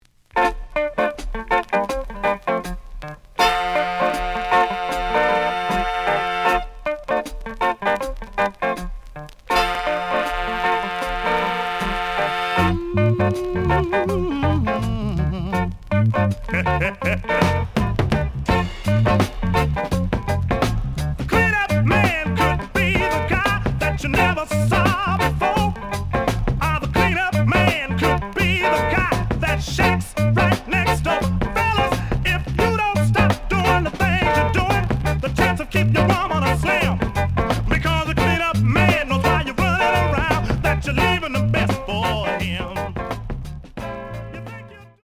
The audio sample is recorded from the actual item.
●Genre: Soul, 70's Soul
Edge warp.